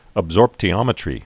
(əb-zôrptē-ŏmĭ-trē, -sôrp-)